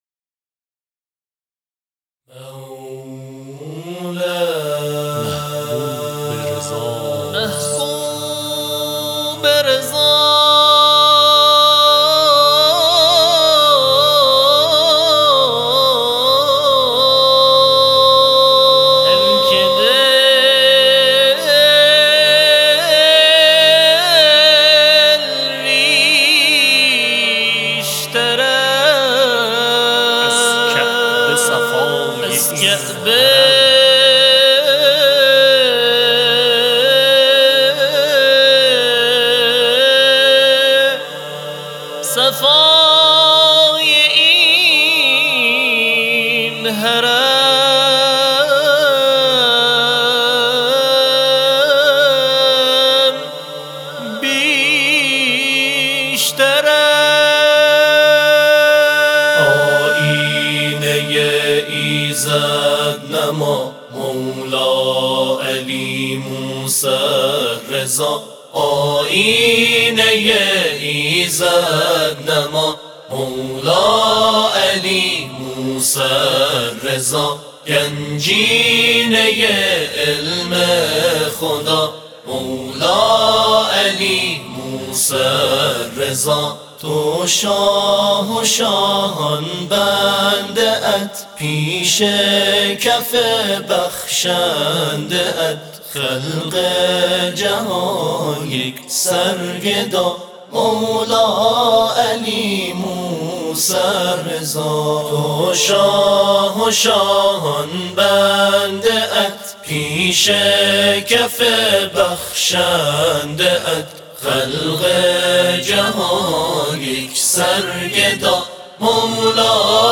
تواشیح